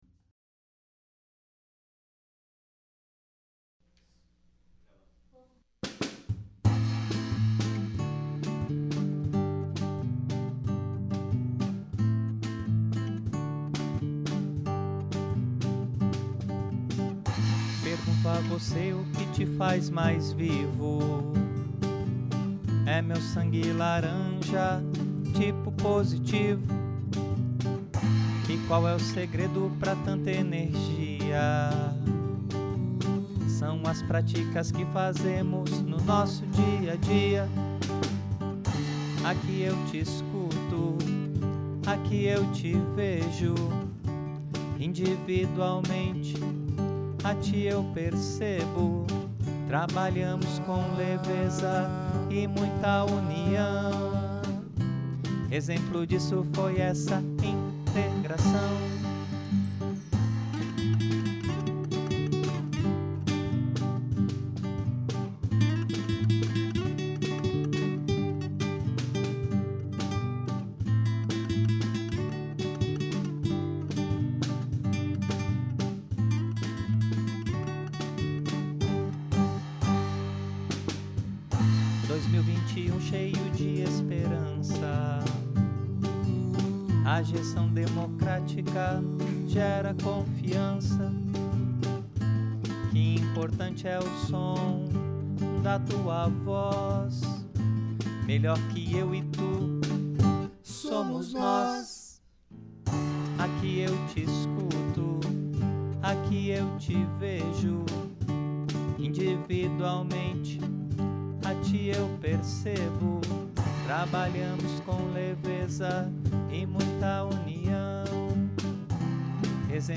O encontro on-line, promovido no fim de janeiro, reuniu gestores, coordenadores e diretores dos colégios do Grupo Positivo em uma ação que possibilitou a integração e a troca de experiências sobre boas-práticas entre todas as unidades.